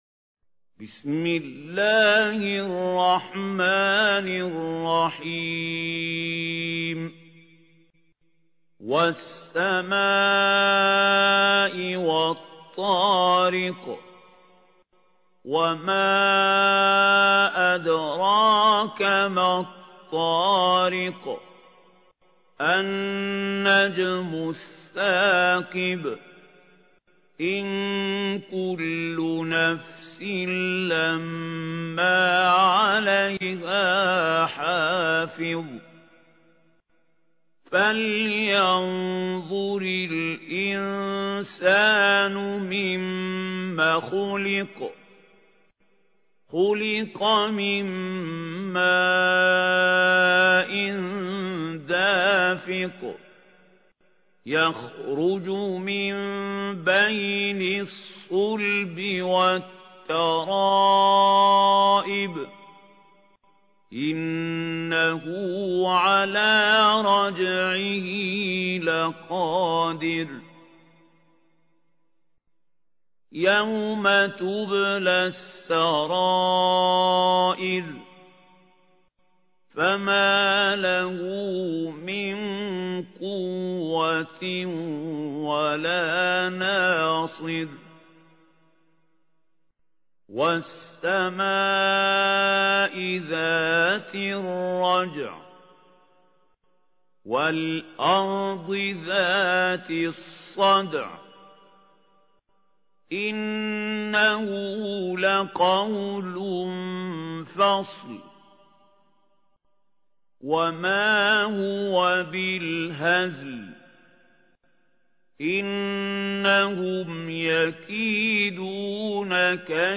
ترتيل
سورة الطارق الخطیب: المقريء محمود خليل الحصري المدة الزمنية: 00:00:00